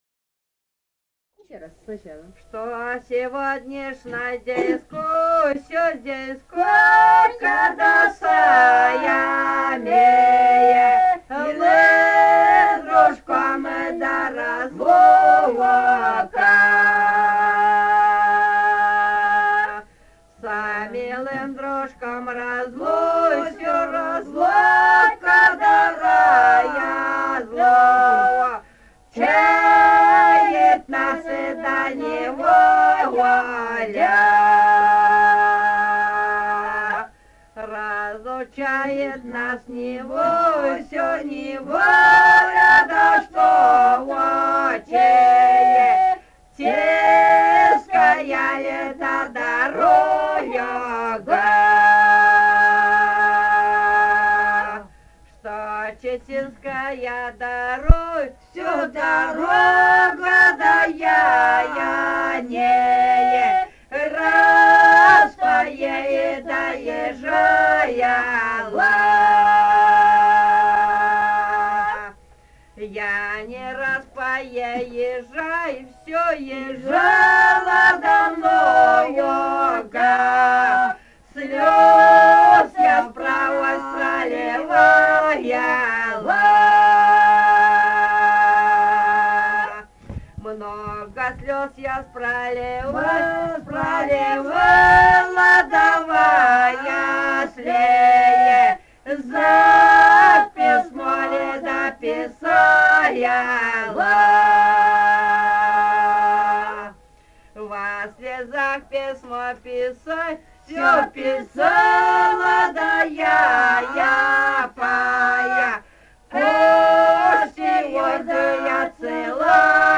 полевые материалы
Бурятия, с. Петропавловка Джидинского района, 1966 г. И0903-08